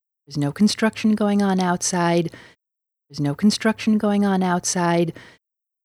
Mic is on a tall stand.
Your voice is cleaner with fewer distortions (attached).